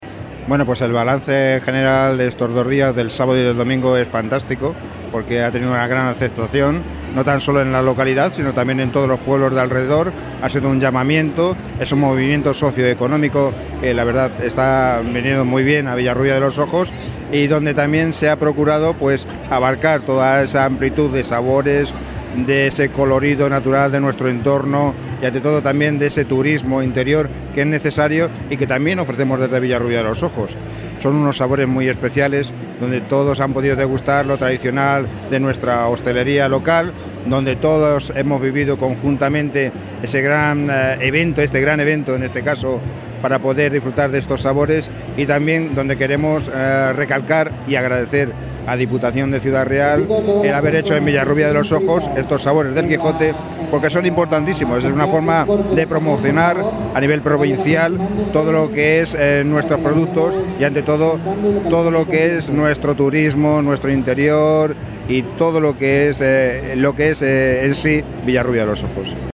audio_alcalde_villarrubia.mp3